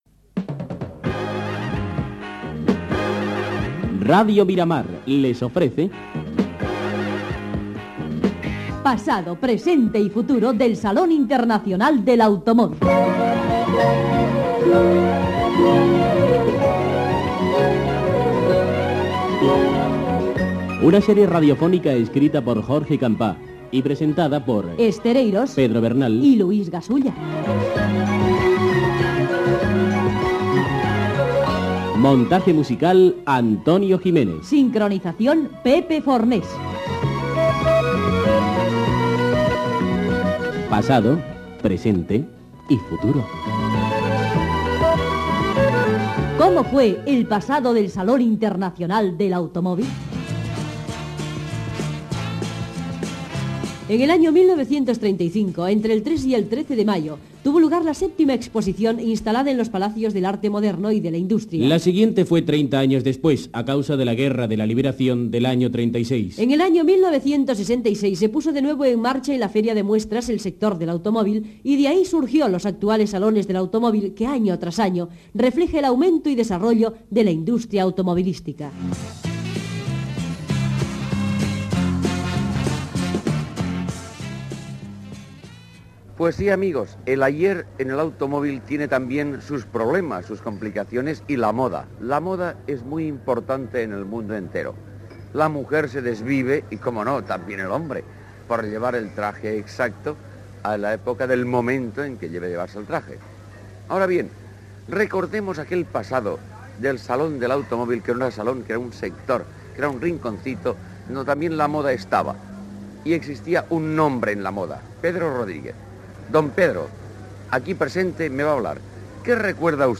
Reportatge sobre el Salón Internacional del l'Automóvil. Entrevista al modist Pedro Rodríguez a l'estand de Mercedes.